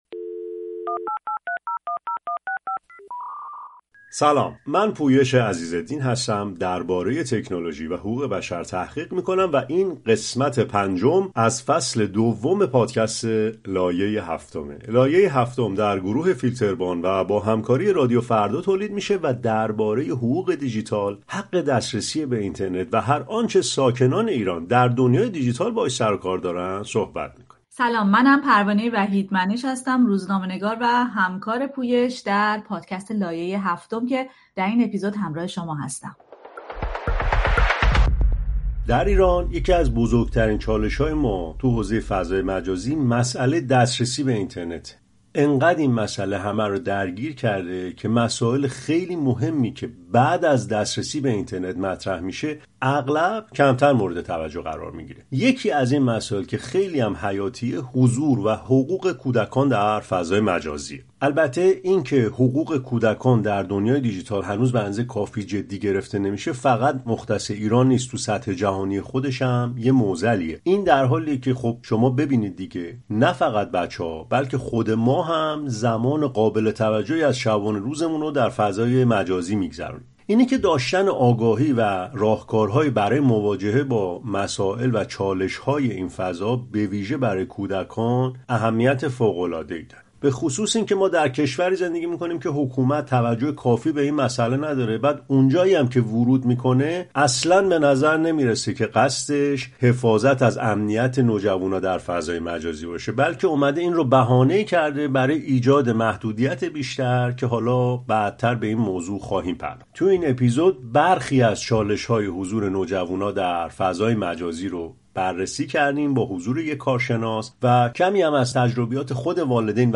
در این برنامه، با کارشناسی در این حوزه گفتگو می‌کنیم، تجربه‌های والدین و کودکان را می‌شنویم و به دنبال راهکارهایی برای ایجاد تعادل بین آزادی و امنیت در